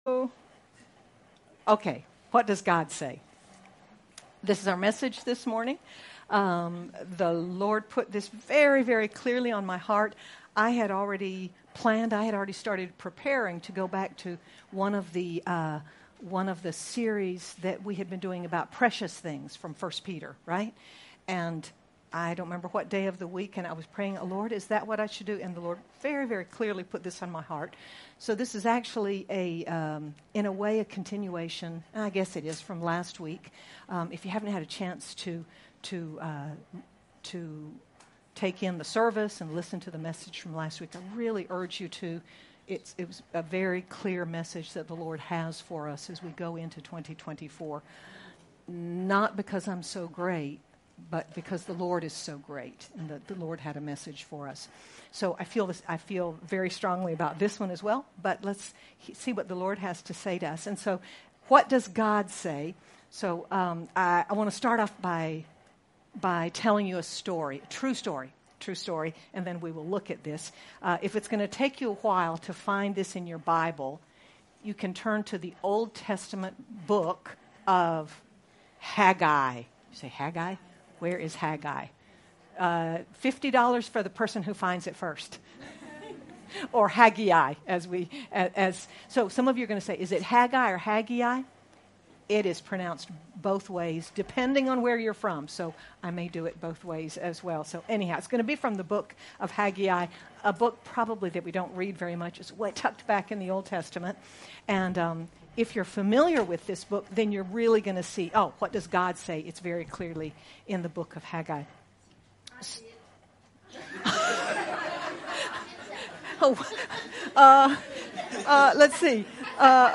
Sermon by